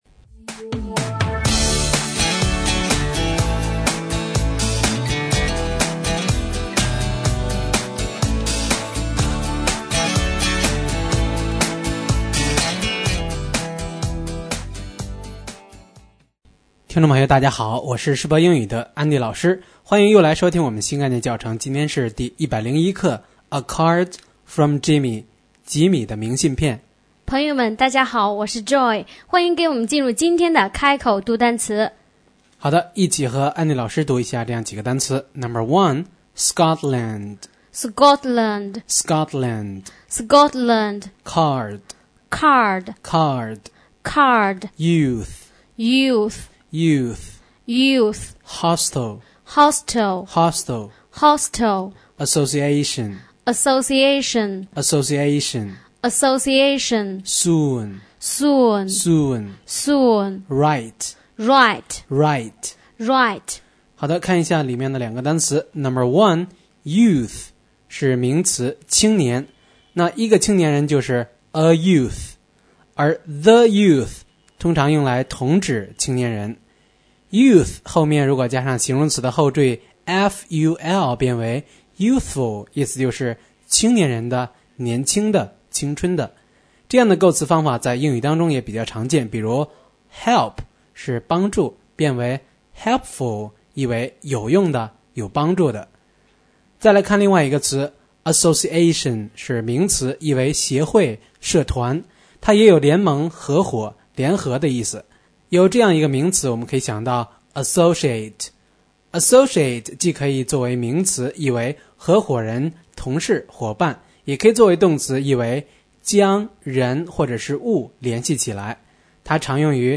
开口读单词